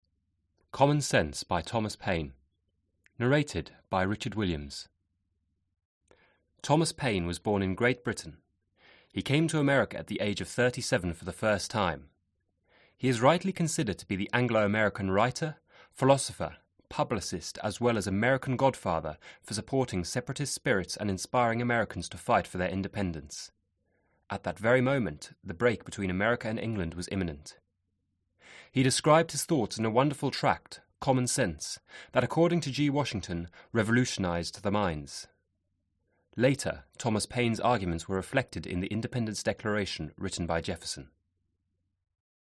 Аудиокнига Common Sense | Библиотека аудиокниг